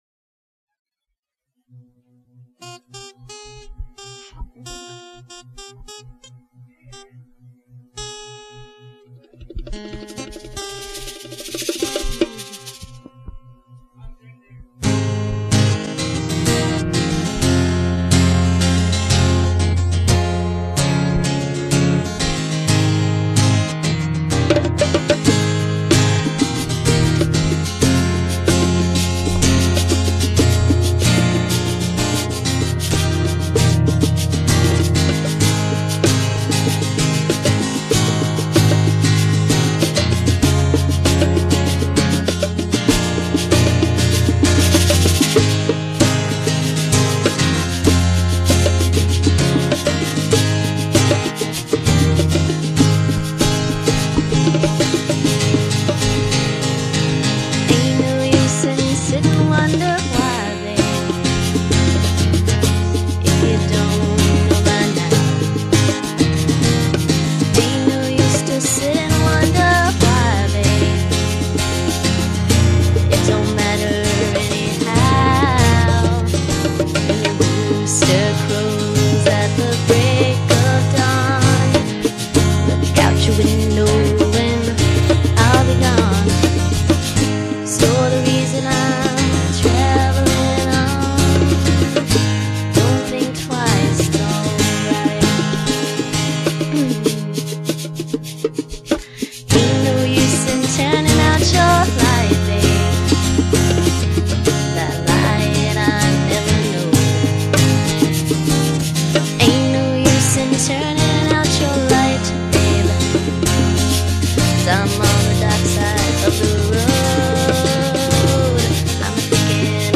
guitar
and I noodle on the bongos and afuche cabasa.